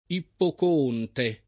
Ippocoonte [ ippoko- 1 nte ]